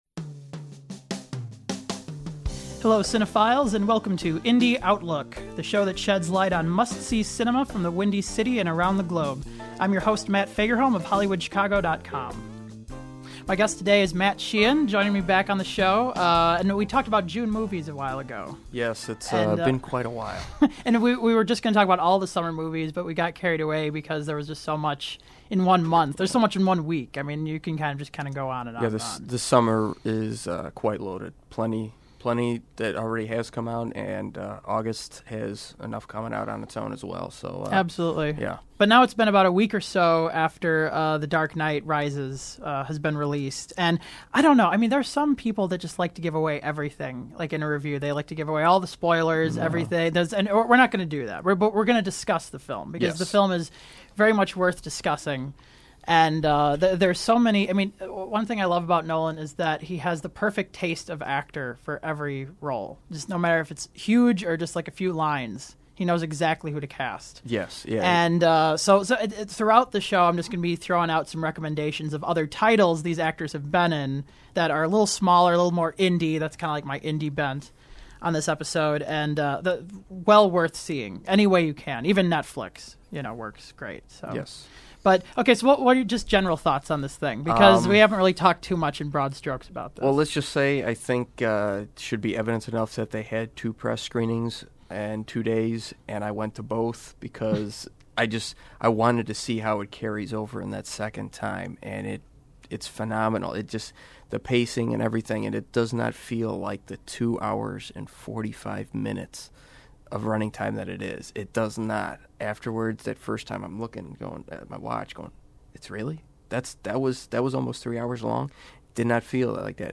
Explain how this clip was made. The program was produced at Columbia College Chicago.